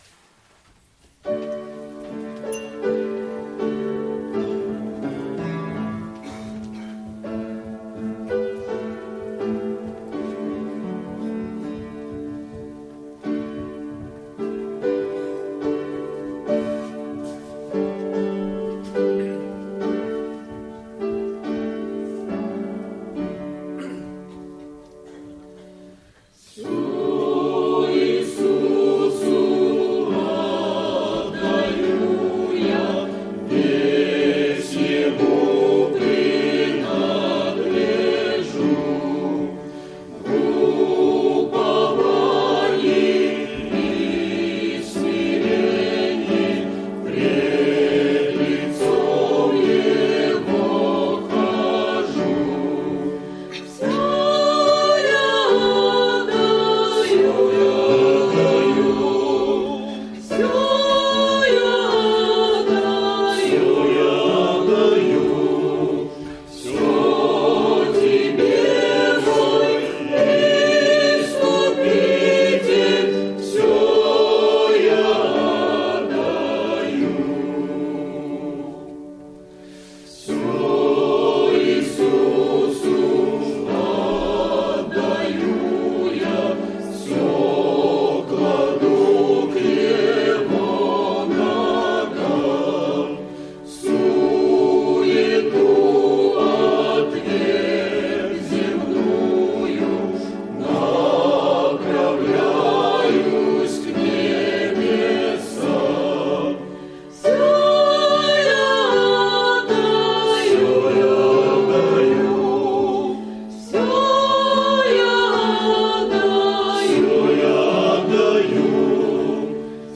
Богослужение 12.09.2010 mp3 видео фото
Все Иисусу отдаю я - Хор (Пение)